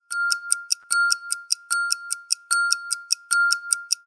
skoleklokke1.mp3